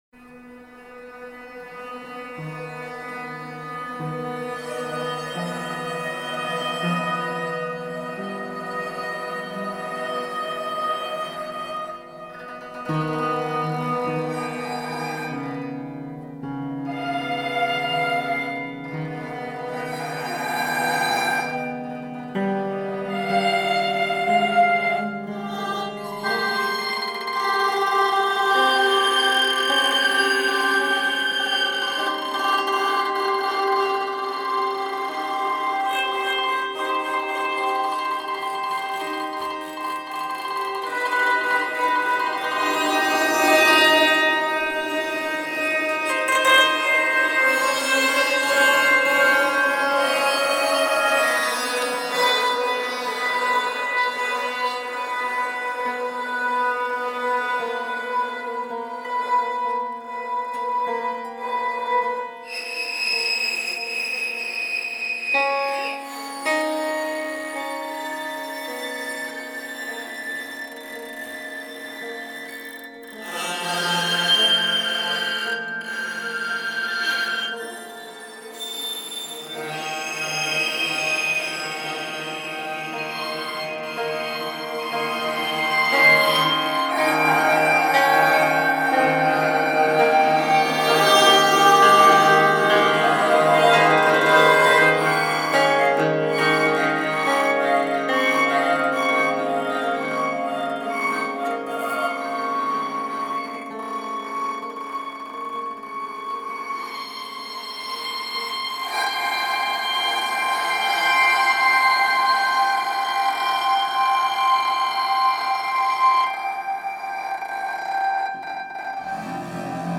CRICKETS PREPARED PIANO
The name “Crickets Prepared Piano” comes from the fact that I did the recordings on a series of summer evenings with distant cricket chirping faintly coloring the sound.
The one in the sound clip involves a secondary string of lightweight monofilament nylon tied around the main strings at one end and free at the other. It’s played by pinching the monofilament between rosined fingers, pulling and letting it slip through to create a friction vibration which activates the main strings.